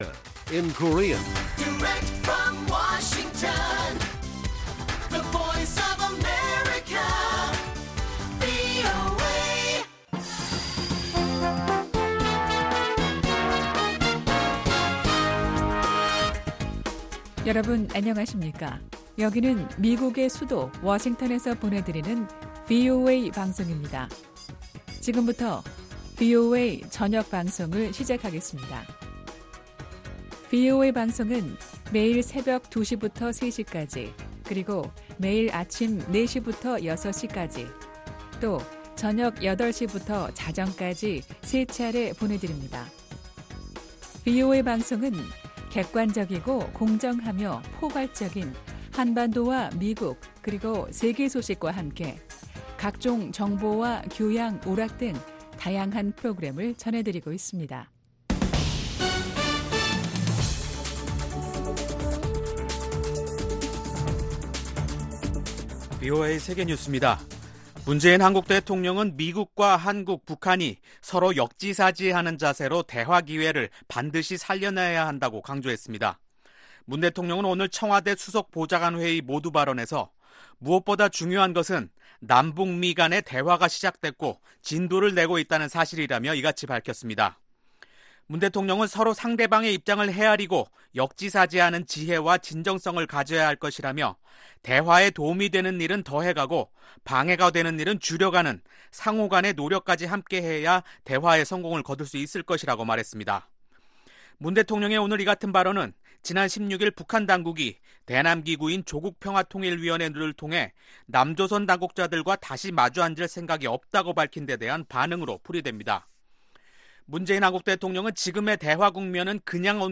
VOA 한국어 간판 뉴스 프로그램 '뉴스 투데이', 2019년 8월 15일 1부 방송입니다. 스티븐 비건 미 국무부 대북특별대표가 이번주 일본과 한국을 방문합니다. 국무부는 북한과 대화할 준비가 돼 있다고 밝혔습니다.